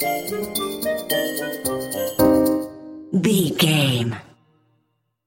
Aeolian/Minor
percussion
flute
orchestra
piano
silly
circus
goofy
comical
cheerful
perky
Light hearted
quirky